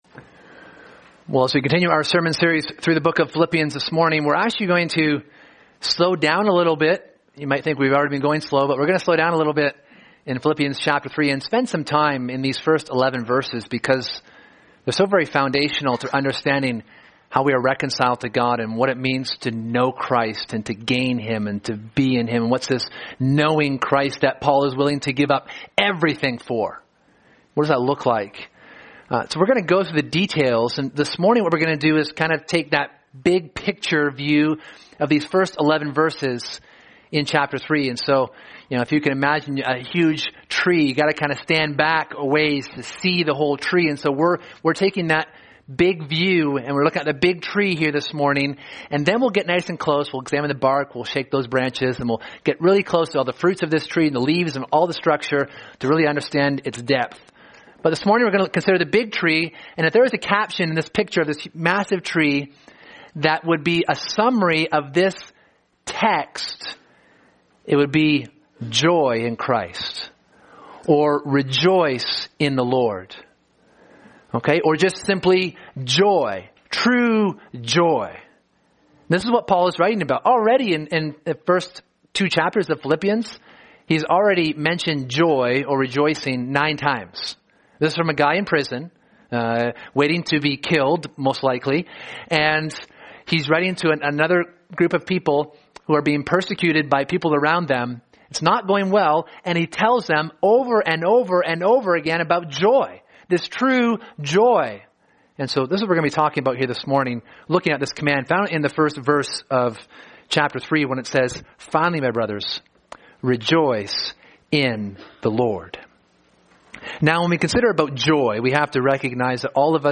Sermon: Joy in Christ